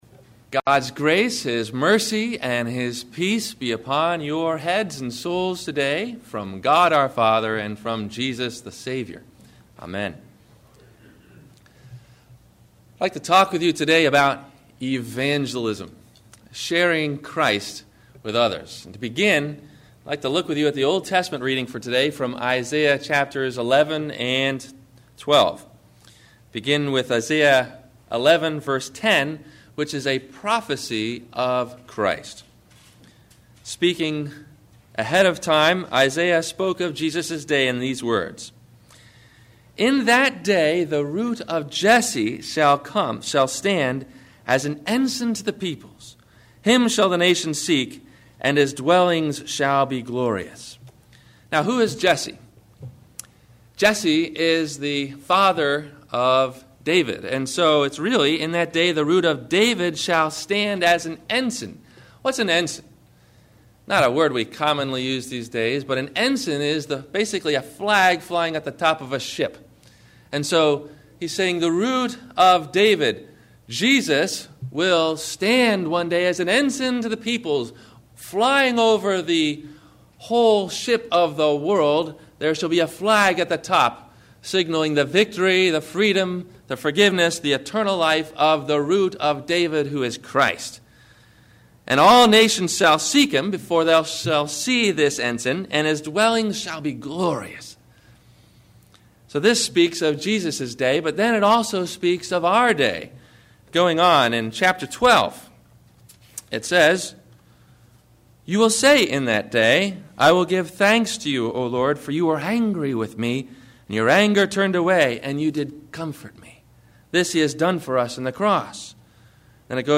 Go – Evangelism – Sermon – January 27 2008